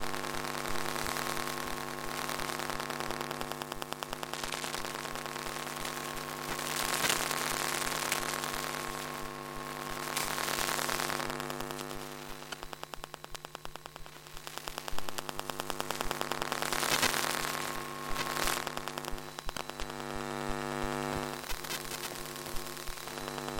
用电话拾音线圈录制的电气设备的声音 " Wlan箱 电气噪音
描述：点击现场录制短咔嗒声毛刺接触式麦克风
标签： 科幻 点击 电子 怪异 数字 奇怪 毛刺 WLAN-盒 电话拾取线圈 随机的 抽象的 怪异的 疯狂的
声道立体声